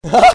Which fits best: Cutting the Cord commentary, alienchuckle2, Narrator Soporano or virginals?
alienchuckle2